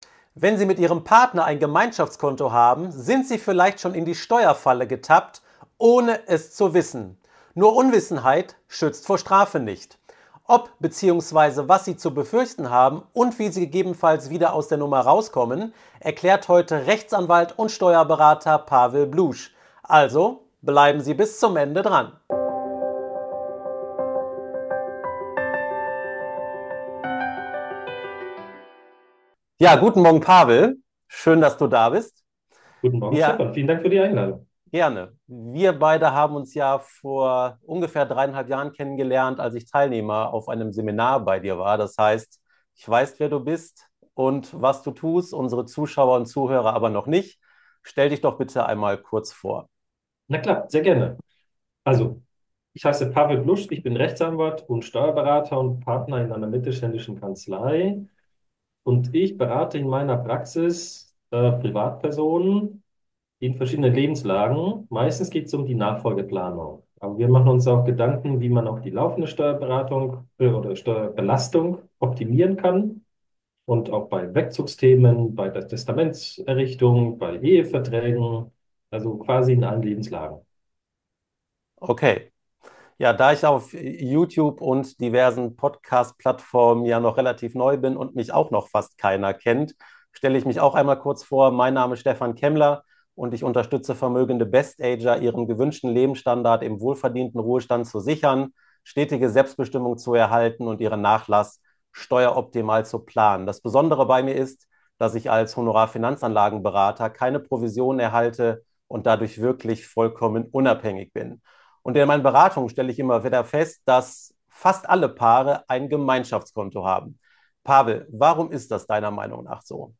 Warum Paare unbewusst Steuern hinterziehen - Interview